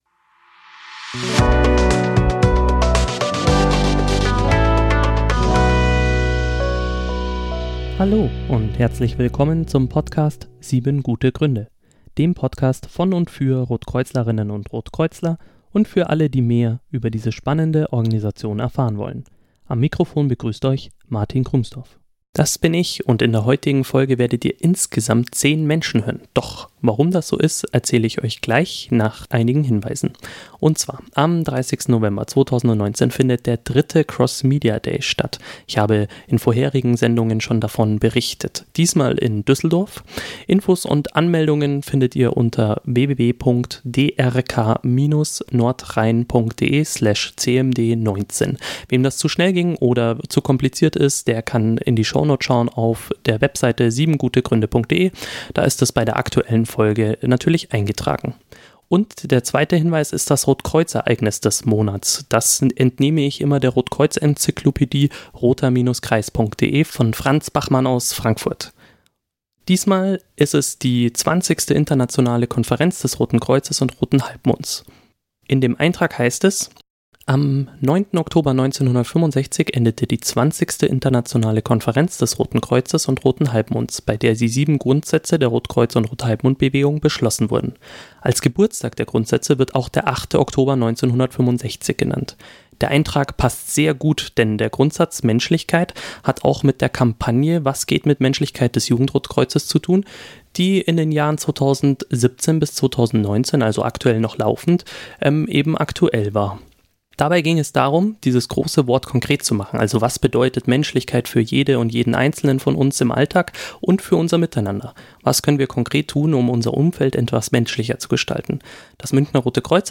Zehn Poet:innen gaben ihre Texte zum Besten. Sie beschäftigten sich mit Menschlichkeit aus verschiedenen Perspektiven, mal humoristisch, mal ernst. Auch Diskriminierungserfahrungen im Alltag waren Thema.
Hier gibt es nun den Mitschnitt der Abendveranstaltung.